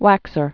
(wăksər)